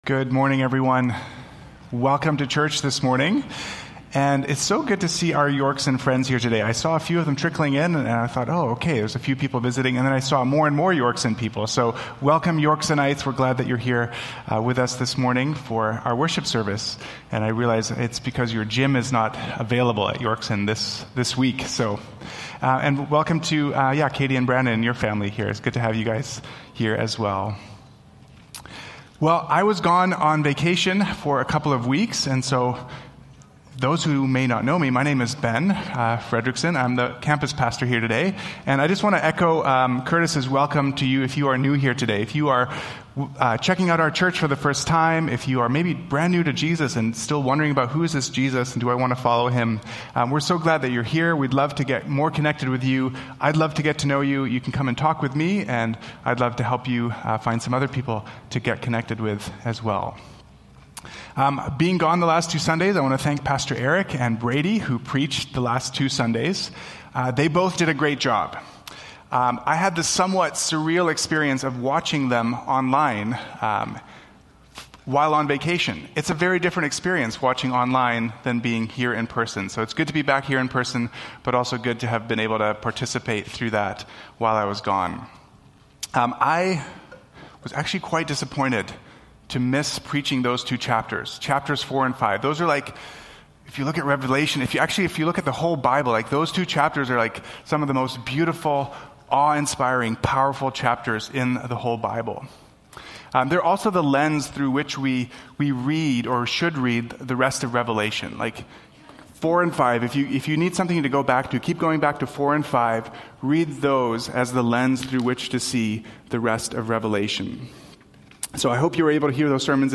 Willowbrook Sermons | North Langley Community Church